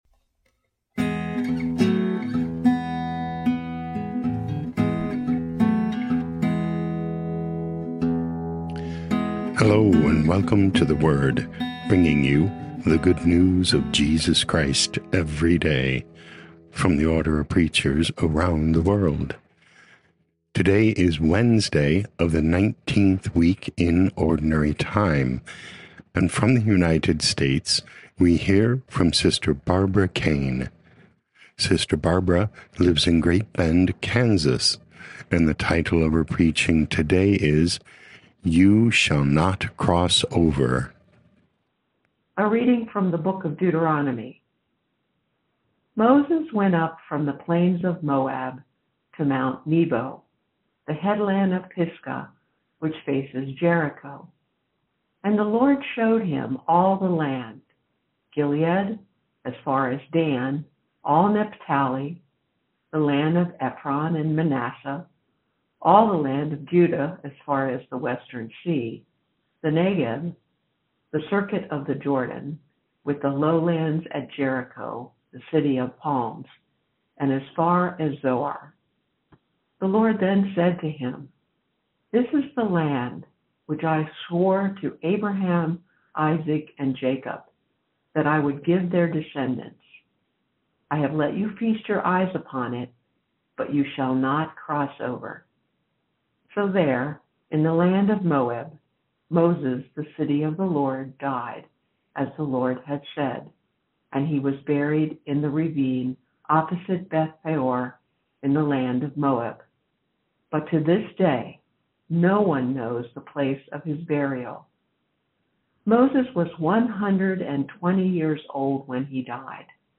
OP Preaching